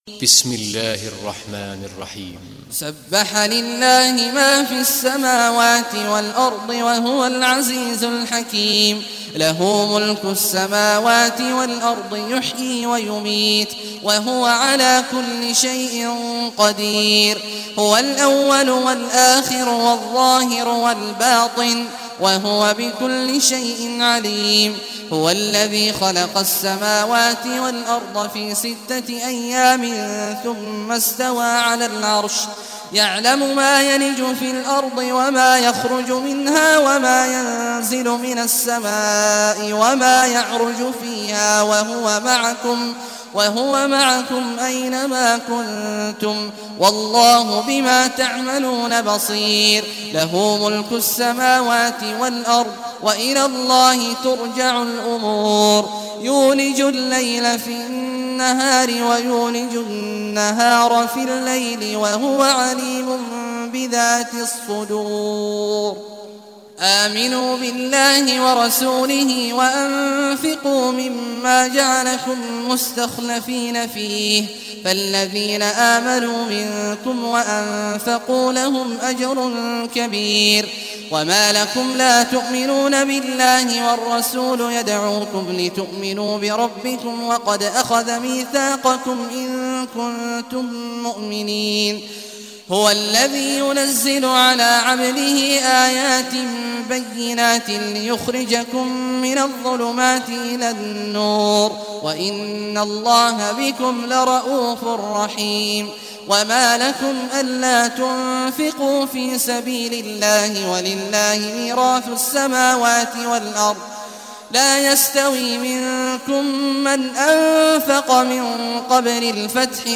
Surah Al-Hadid, listen or play online mp3 tilawat / recitation in Arabic